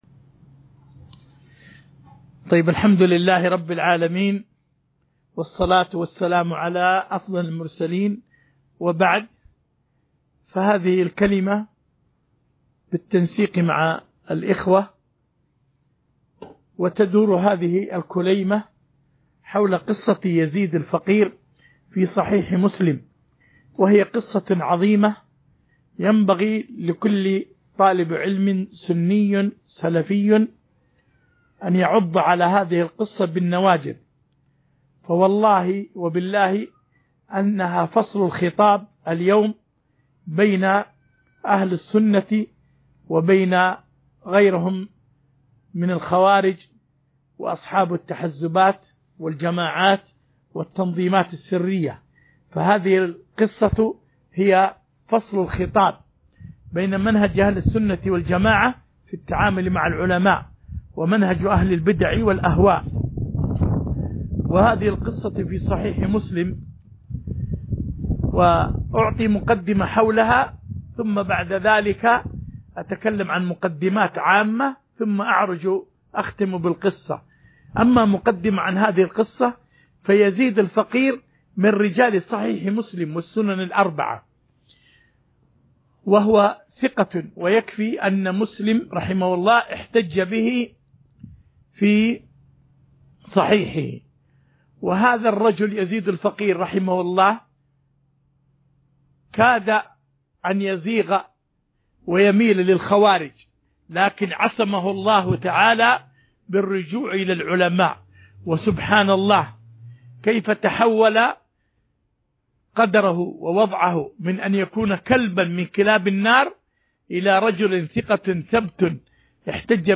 محاضرات